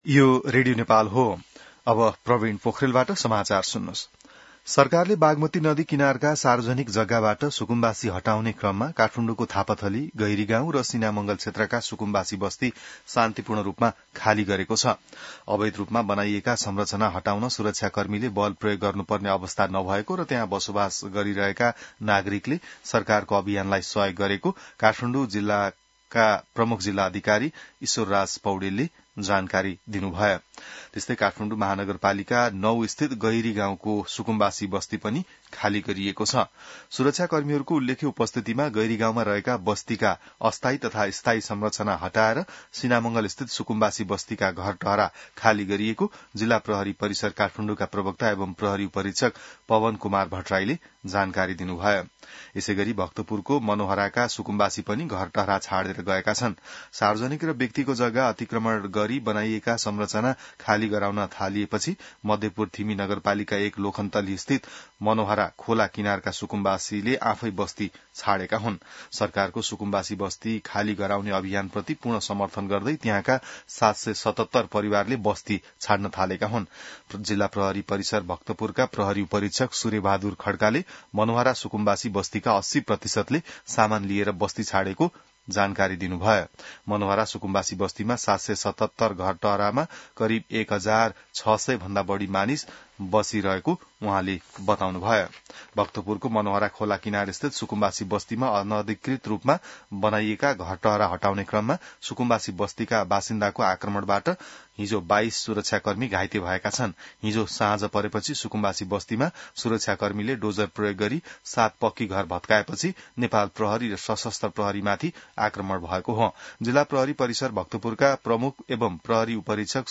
बिहान ६ बजेको नेपाली समाचार : १३ वैशाख , २०८३